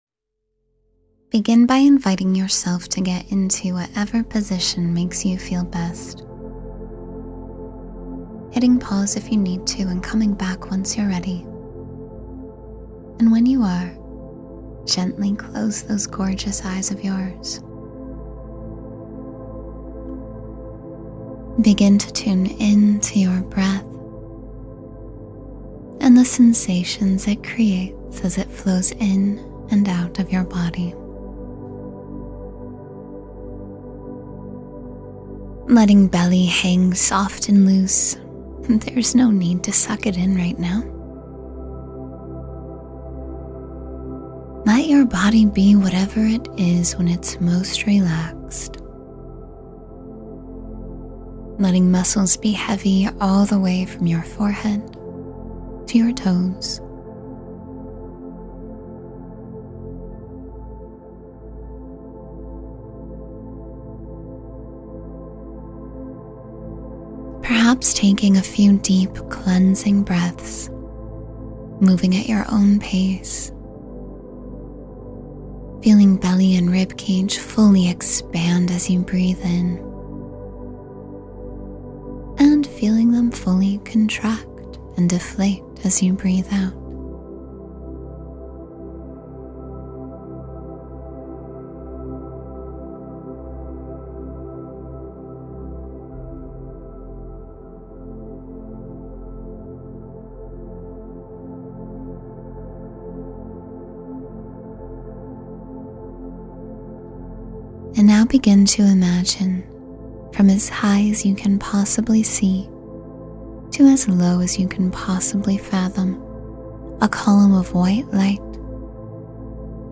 Drift into Deep Sleep and Inner Stillness — Guided Meditation for Restful Sleep